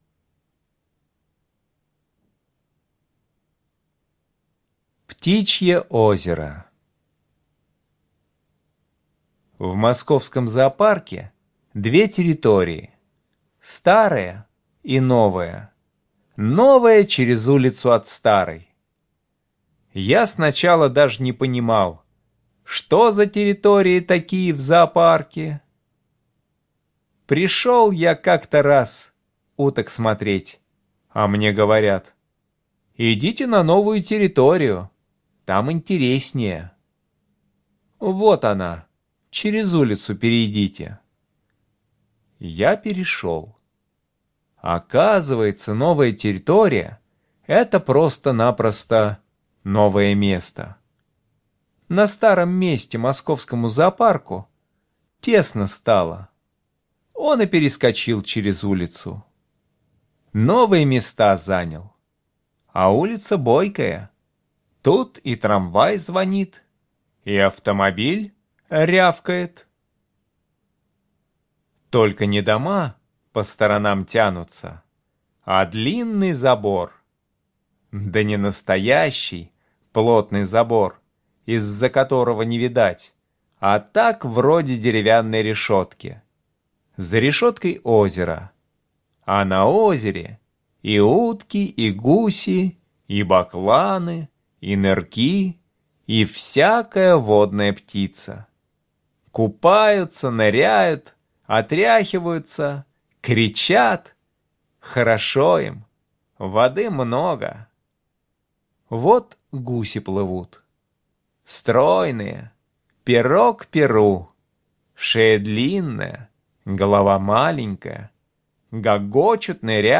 Слушайте Птичье озеро - аудио рассказ Чарушина Е.И. В московском зоопарке есть озеро, на котором живет много разных птиц: утки, гуси, бакланы, нырки.